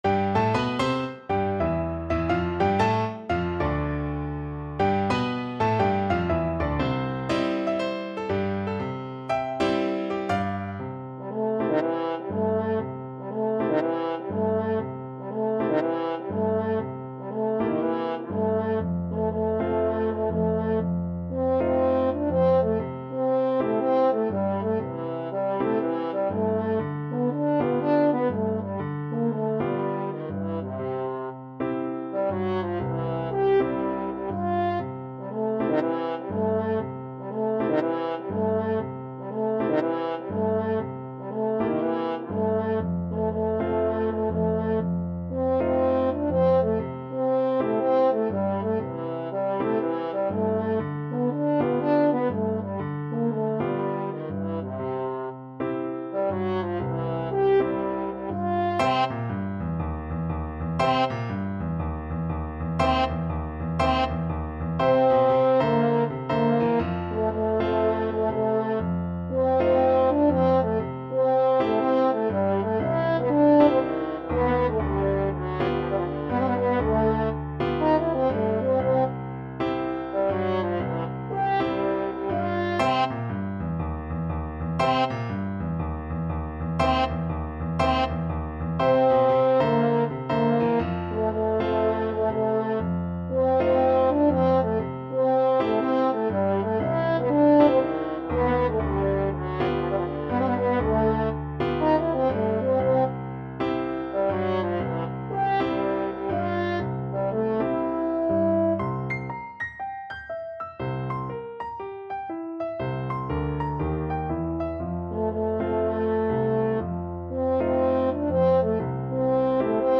4/4 (View more 4/4 Music)
Moderato = 120
Jazz (View more Jazz French Horn Music)